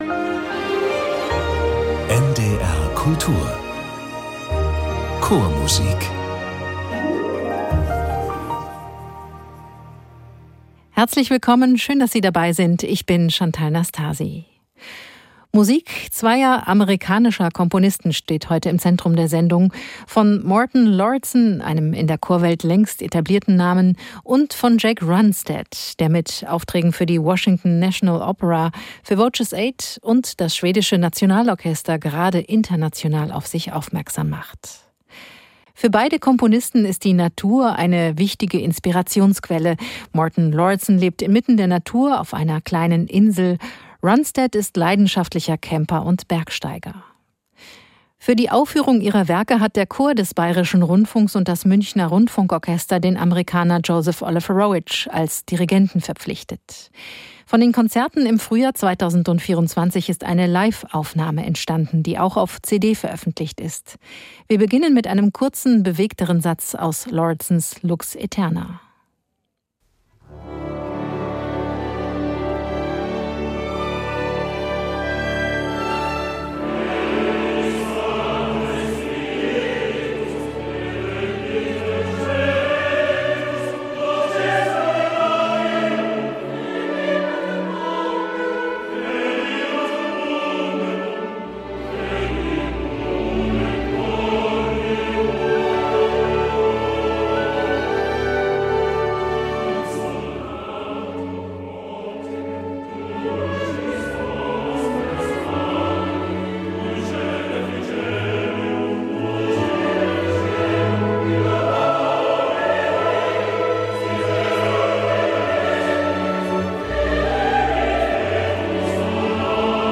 Amerikanische Chormusik mit dem BR Chor ~ Chormusik - Klangwelten der Vokalmusik entdecken Podcast